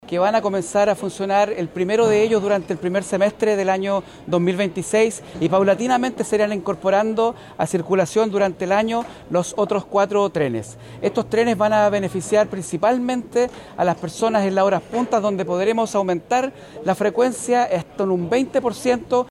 El subsecretario de Transporte, Jorge Daza, entregó detalles de cómo se irán incorporando estos trenes al material rodante del servicio que conecta el puerto con el interior de la región.